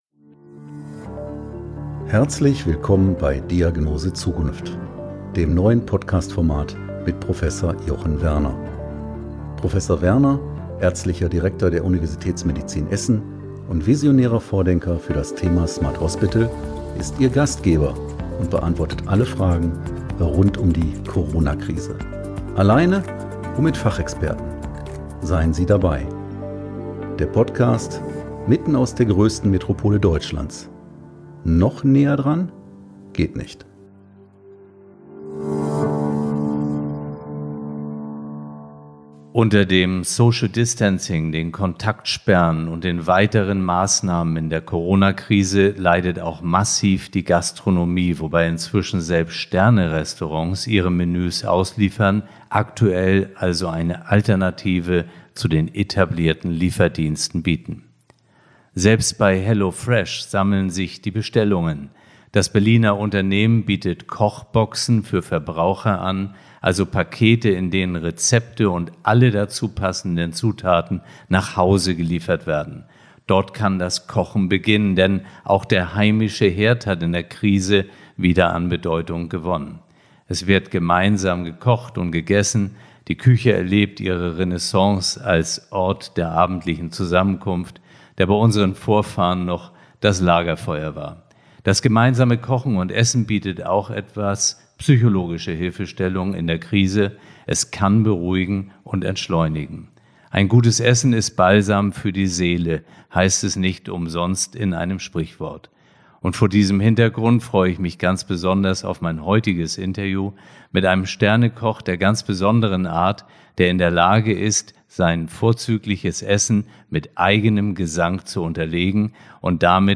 Und wer Nelson Müller etwas besser kennt, der weiß, dass dieses Interview auch von spannenden musikalischen und sehr menschlichen Momenten geprägt ist!